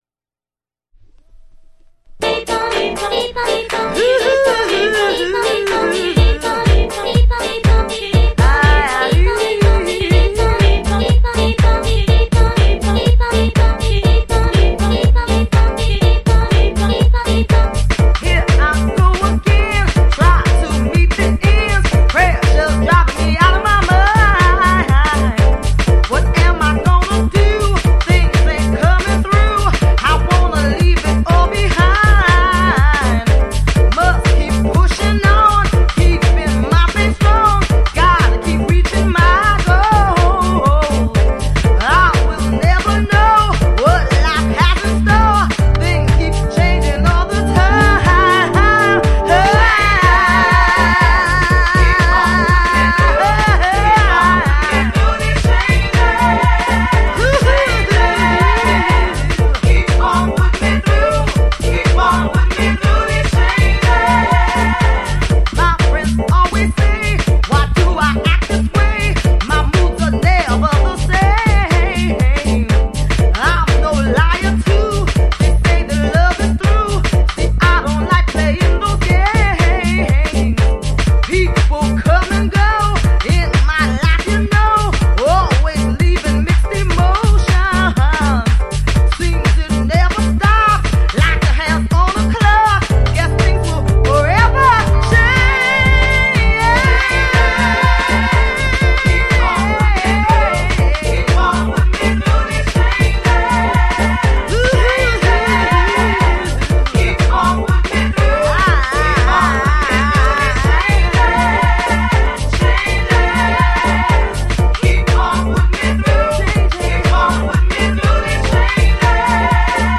TOP > Early House / 90's Techno > VARIOUS